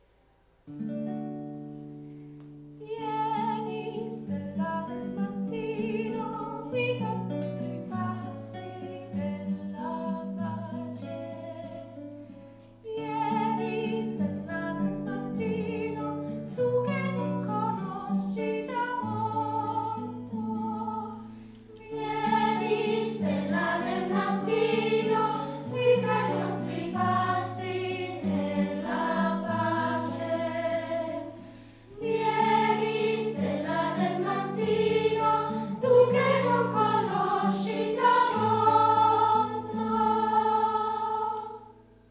Questo ritornello – in una forma di ripetizione con variazione finale – può scandire il cammino dell’Avvento, orientandolo non solo verso la luce di Betlemme, ma soprattutto verso l’incontro finale con Cristo, la cui presenza non conoscerà tramonto.
Se lo si esegue come antifona allo Spezzare del Pane, la risposta dell’assemblea può seguire la proposta di un solista.
CD183_VieniStellaDelMattino_voci-bianche-Corale-Arnatese.mp3